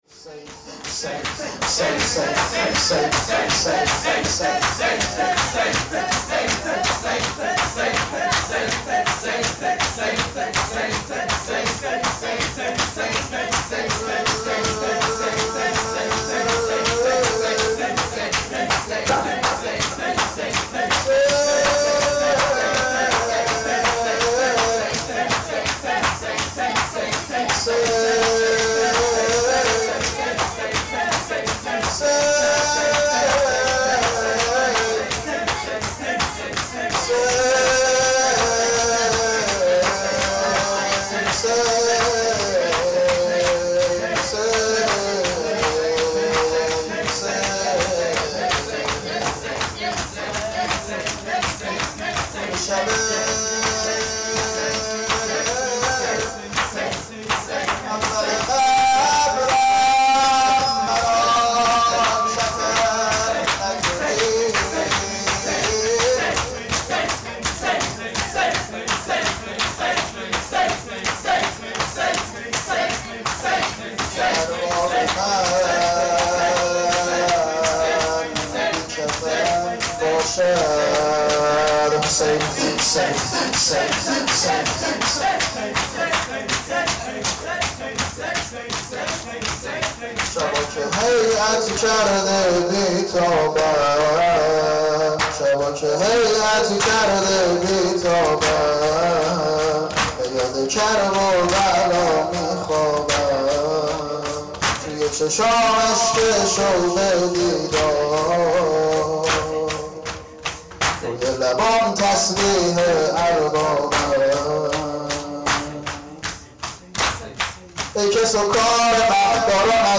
• مراسم سینه زنی شب هشتم محرم هیئت روضه الحسین
شور-شبا-که-هیئت-کرده-بی-تابم.mp3